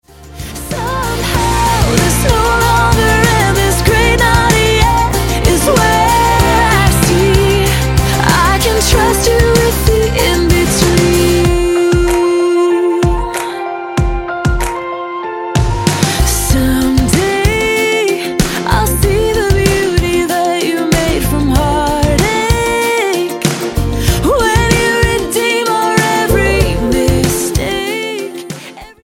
singer/songwriter
Style: Roots/Acoustic